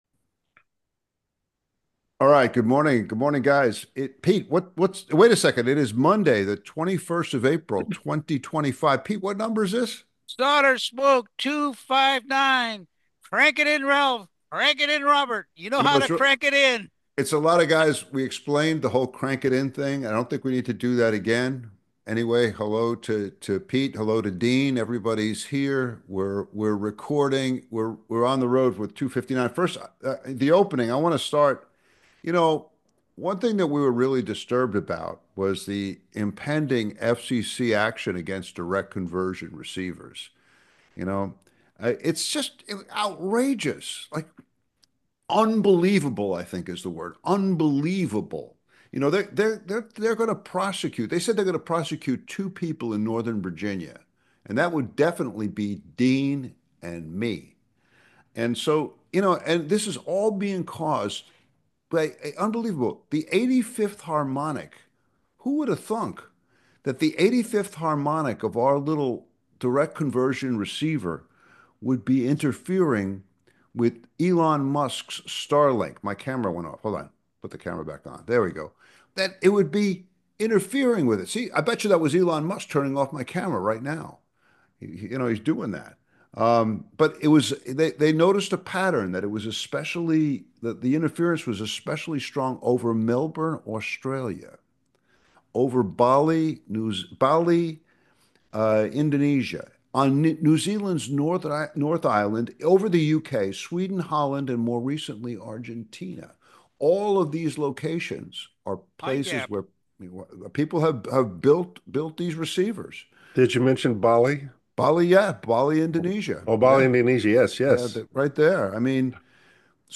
Two amateur radio experimenters discuss workbench radio projects